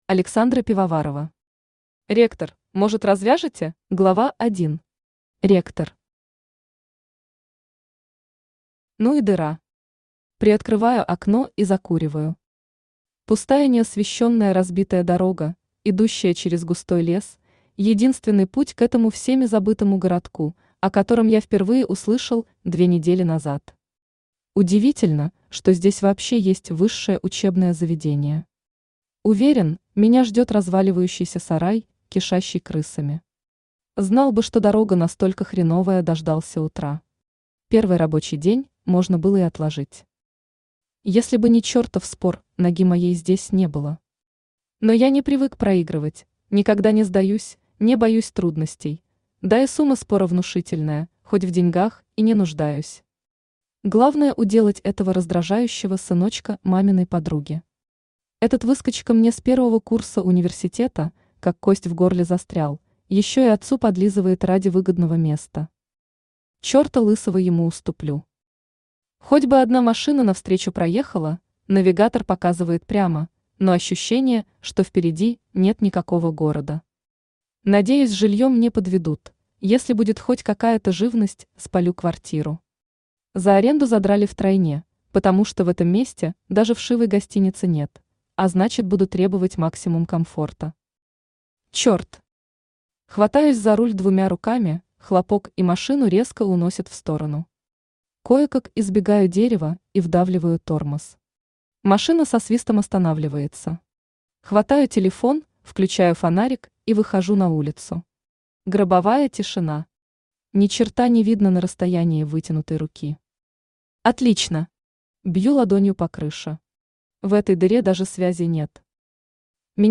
Аудиокнига Ректор, может, развяжете?
Автор Александра Пивоварова Читает аудиокнигу Авточтец ЛитРес.